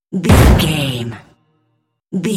Cinematic stab hit trailer
Sound Effects
heavy
intense
dark
aggressive
the trailer effect